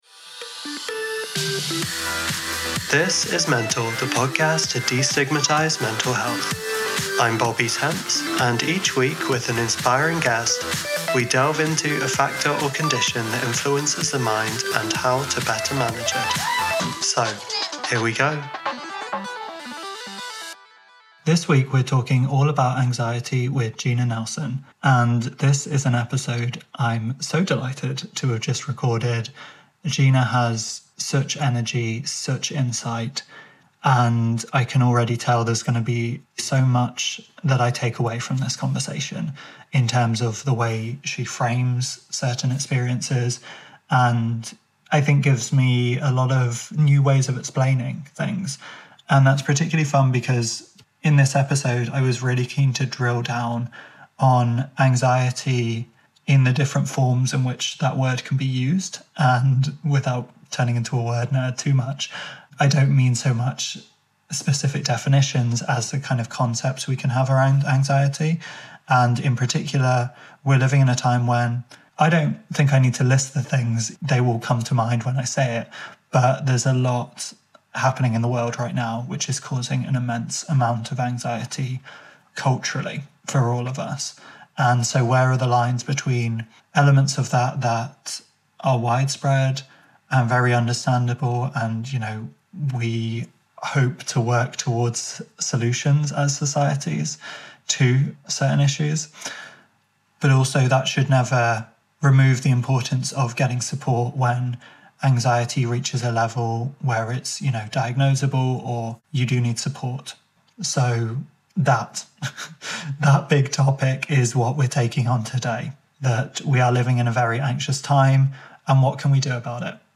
It makes for a very relatable and practical conversation.